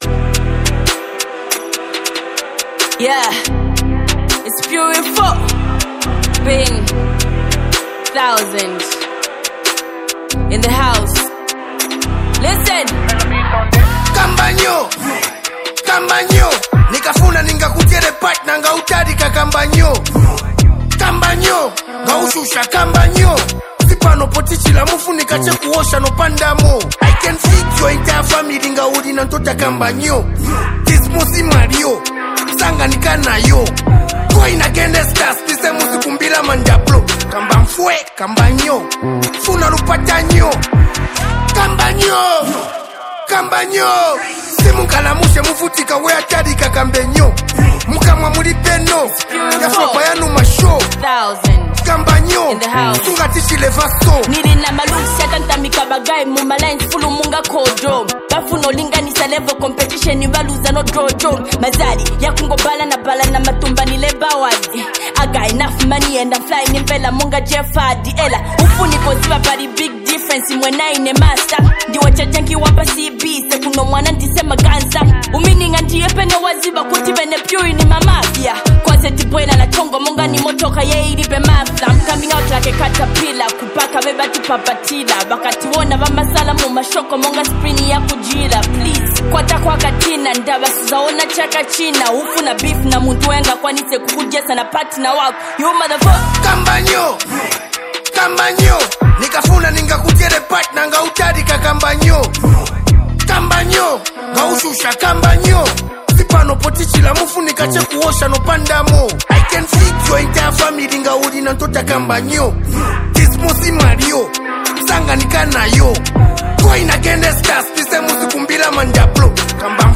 ” a track packed with energy and street wisdom.